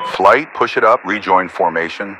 Radio-playerWingmanRejoin2.ogg